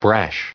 added pronounciation and merriam webster audio
2007_brash.ogg